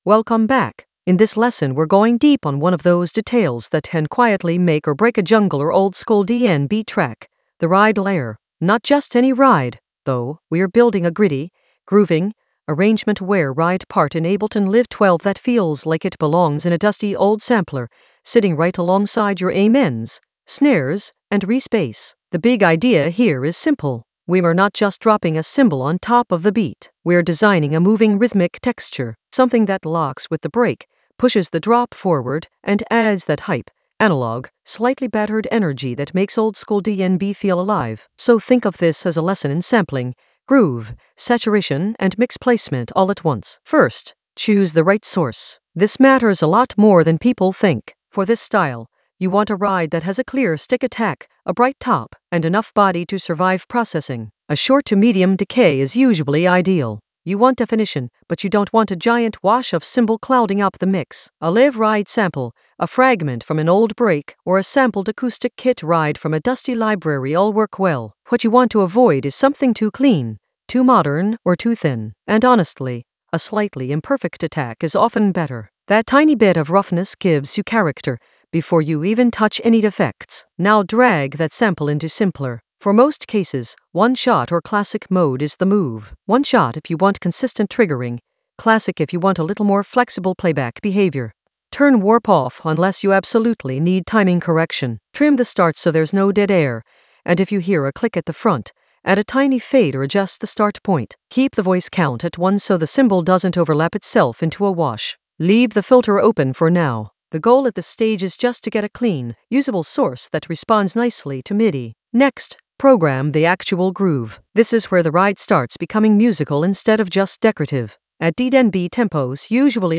An AI-generated advanced Ableton lesson focused on Think deep dive: ride groove saturate in Ableton Live 12 for jungle oldskool DnB vibes in the Sampling area of drum and bass production.
Narrated lesson audio
The voice track includes the tutorial plus extra teacher commentary.